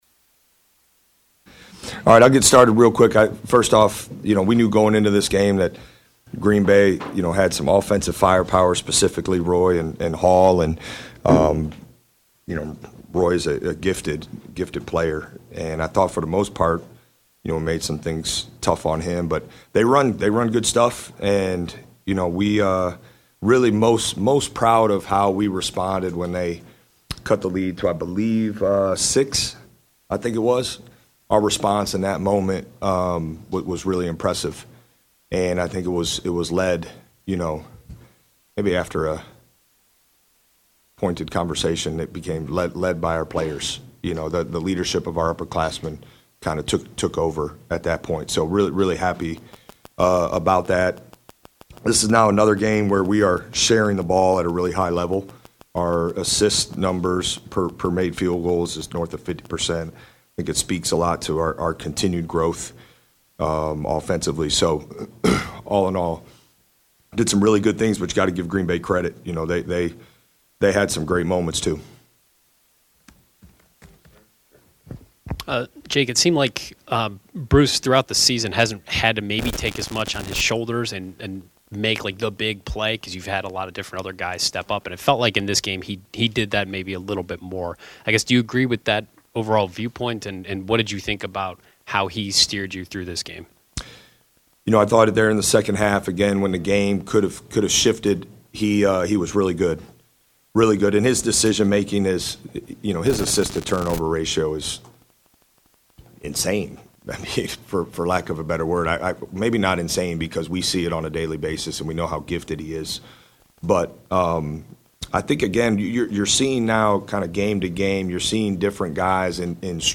Ohio State Men’s Basketball deep team continues to wear opponents down; Jake Diebler Postgame Press Conference following Green Bay win